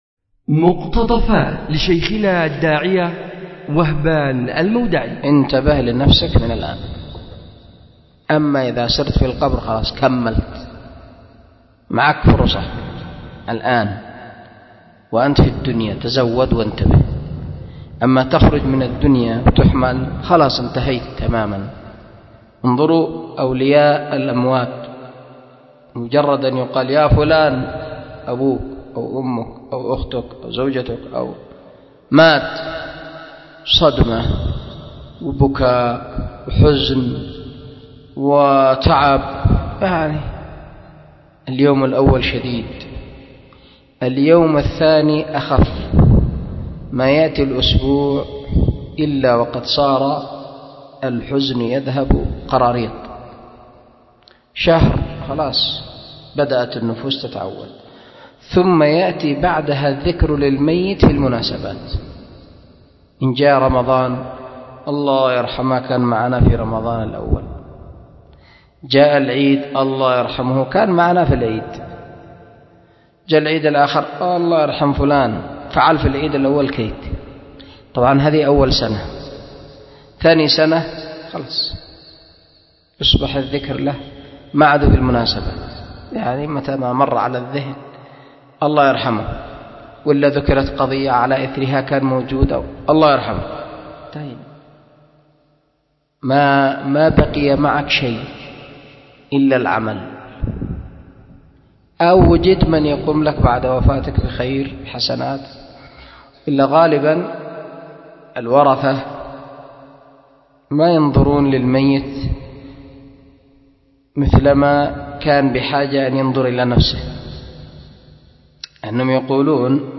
أُلقي بدار الحديث للعلوم الشرعية بمسجد ذي النورين ـ اليمن ـ ذمار